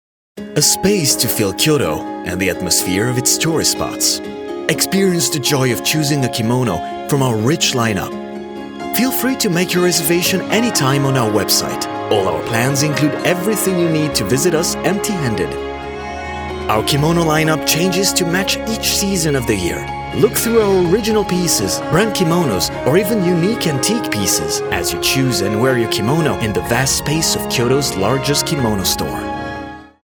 Narration
My natural voice lies in the middle-low range and is perfect to inspire and create trust with a touch of warmth and accessibility – ideal for corporate jobs, e-learnings and other “serious” projects that demand a reliable & inspiring yet warm and friendly delivery.